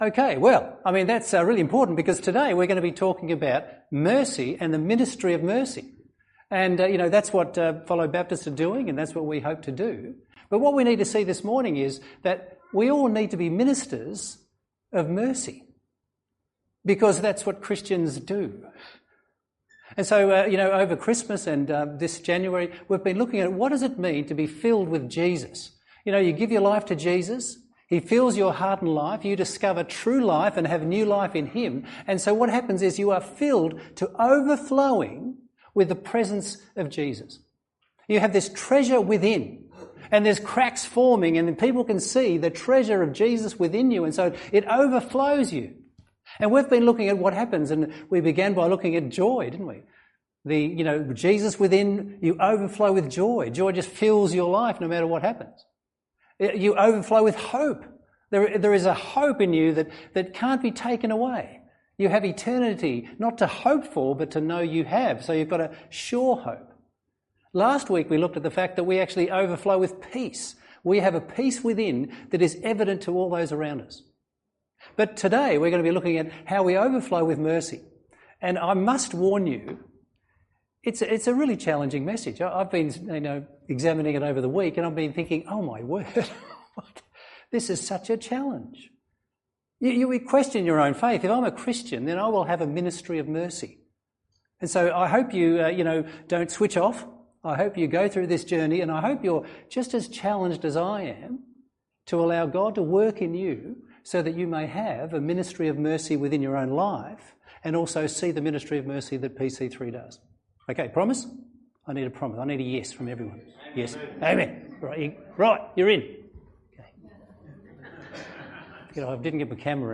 by PC3 | Feb 2, 2025 | Sermons | 0 comments